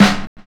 Snare set 2 007.wav